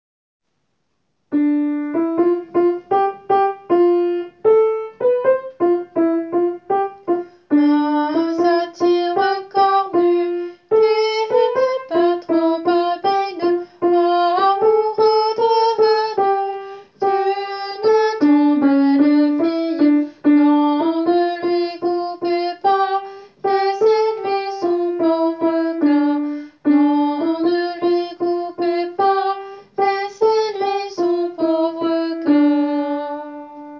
Soprano :
satire-soprano.wav